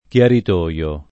[ k L arit 1L o ]